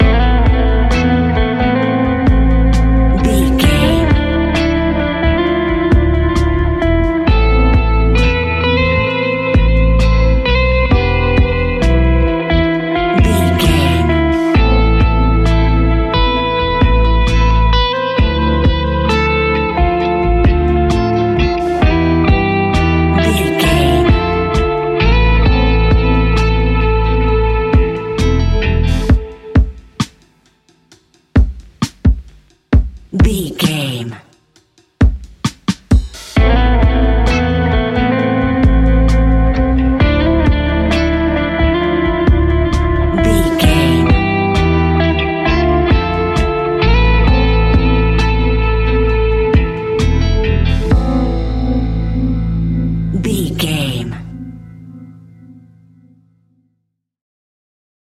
Ionian/Major
A♭
chilled
laid back
Lounge
sparse
new age
chilled electronica
ambient
atmospheric
morphing